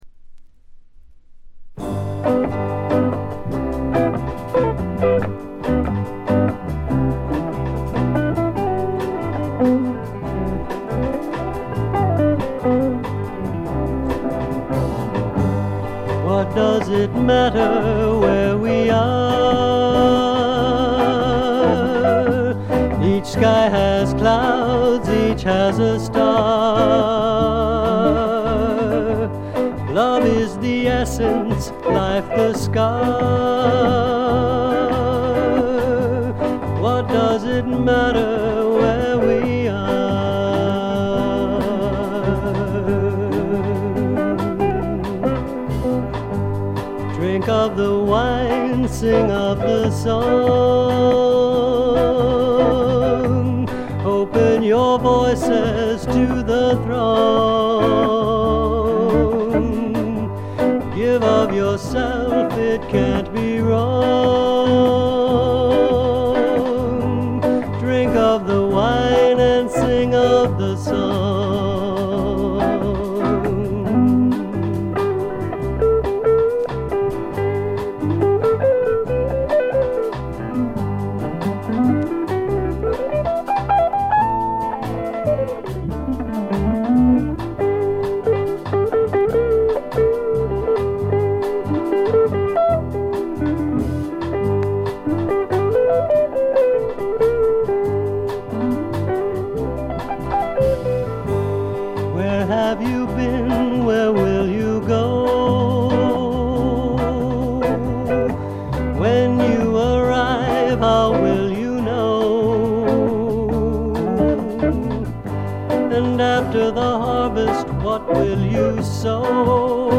というわけで一度聴いたらクリアトーンのギターの音色が頭から離れなくなります。
特異な世界を見せつけるアシッド・フォークの傑作です。
試聴曲は現品からの取り込み音源です。
Guitar, Vocals, Producer, Written-By, Arranged By ?